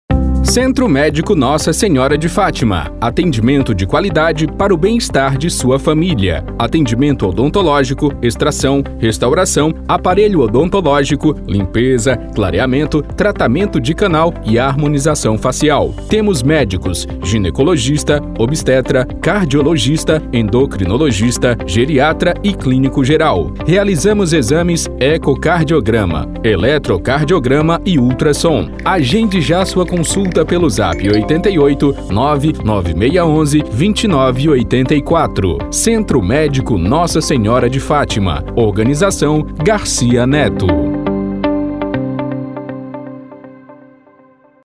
Demo Centro Médico Padrão:
Spot Comercial